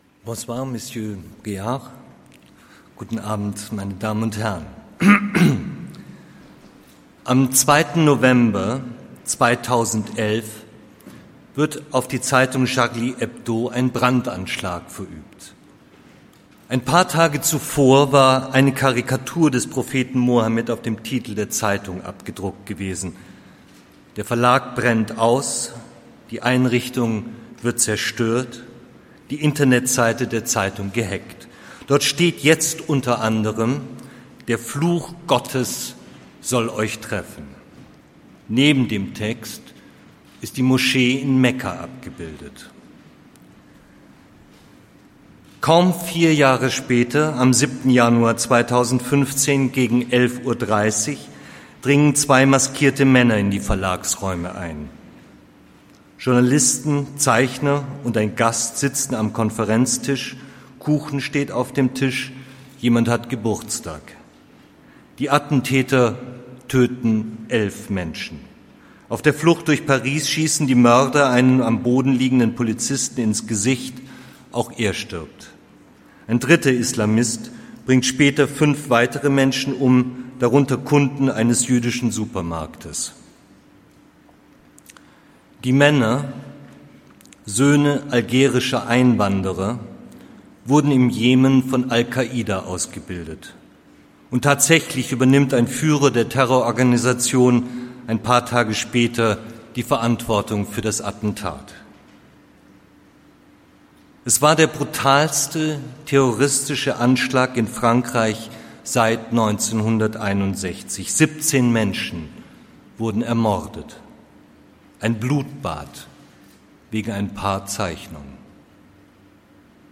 Wer: Frank-Walter Steinmeier, Bundesaußenminister
Hauptrede Verleihung M100-Sanssouci Media Award 2015 Wo: Orangerie Schloss Sanssouci, Potsdam